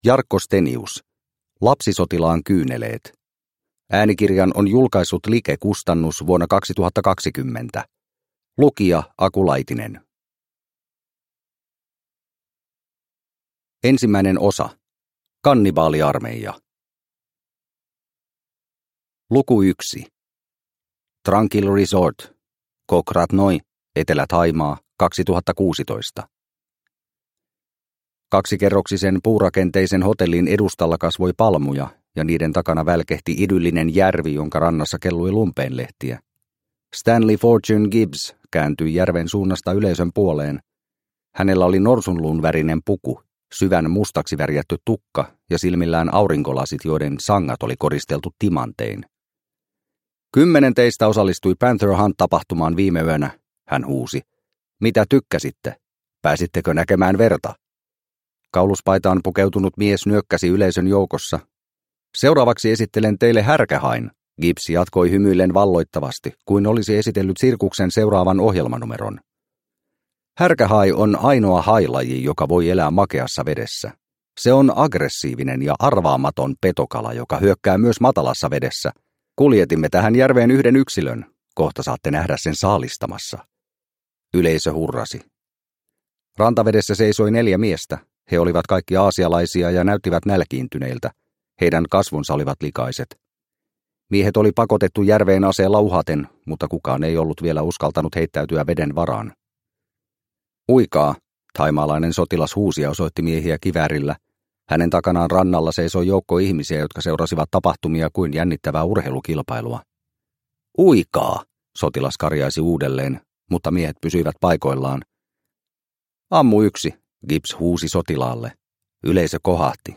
Lapsisotilaan kyyneleet – Ljudbok – Laddas ner